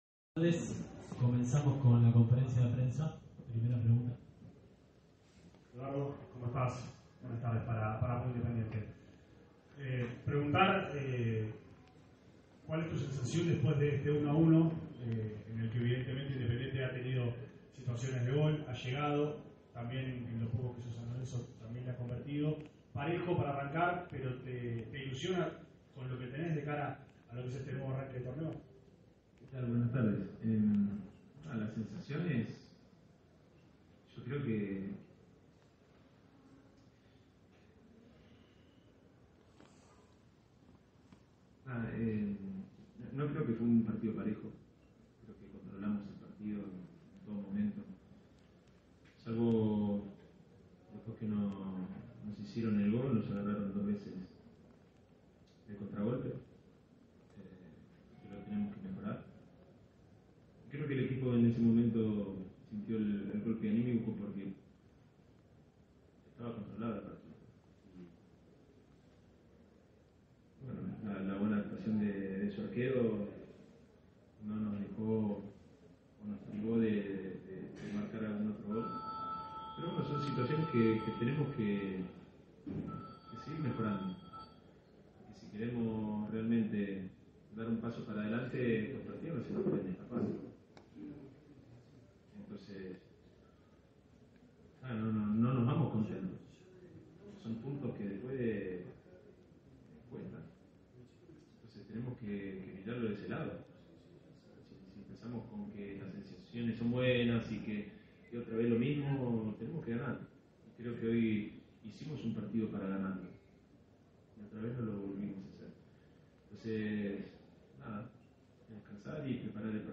Independiente siente que se le escaparon dos puntos en el inicio de la Liga Profesional 2022. El empate ante San Lorenzo en el Nuevo Gasómetro no dejó conforme a Eduardo Domínguez, quien consideró en conferencia de prensa que el rival se llevó más de lo que mereció durante los 90 minutos.